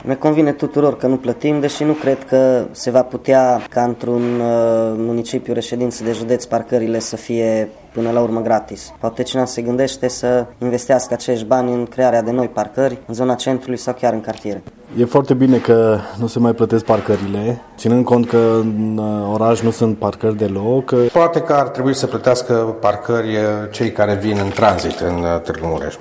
Conducătorii auto nu sunt deloc supărați că nu mai trebuie să plătească parcare. Ei au și soluții pentru tarife, care, spun ei, ar trebui să se aplice celor care nu locuiesc în municipiu: